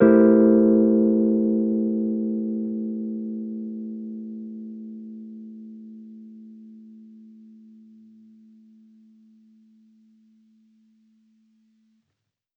Index of /musicradar/jazz-keys-samples/Chord Hits/Electric Piano 1
JK_ElPiano1_Chord-Am7b9.wav